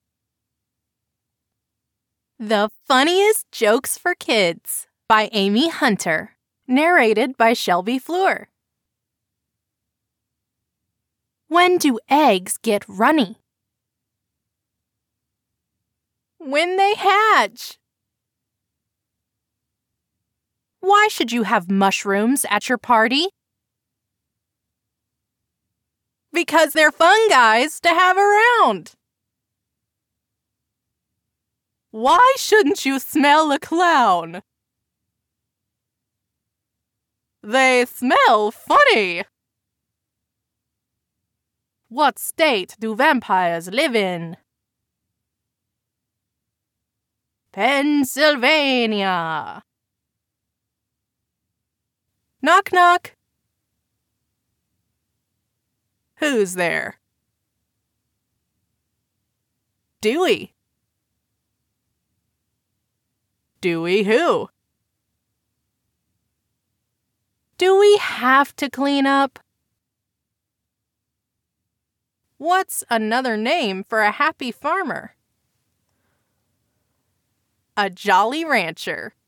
CLIENTS DESCRIBE MY VOICE AS: accessible, amusing, animated, announcer, articulate, athletic, attractive, authentic, authoritative, approachable, believable, brave, bold, calm, caring, cartoon, captivating, casual, character, charming, classy, comforting, commanding, confident, conversational, cool, corporate, comedic, credible, curious, determined, dramatic, dynamic, easy-going, edgy, educational, elegant, endearing, energetic, engaging, enthusiastic, exaggerated, excited, executive, familiar, friendly, fun, funny, gentle, genuine, grounded, happy, humorous, improv, informative, inspirational, intelligent, inviting, knowledgeable, laid back, likable, motivational, mysterious, natural, neighborly, persuasive, playful, powerful, pro, real, reassuring, relatable, reflective, sexy, sarcastic, serious, sincere, smooth, soft, soothing, sophisticated, storyteller, straightforward, strong, stylish, suave, sultry, sweet, smart, thoughtful, trusting, trustworthy, upbeat, uplifting, velvety, versatile, witty, warm, quirky.
I have a broadcast-quality professional studio with Source Connect.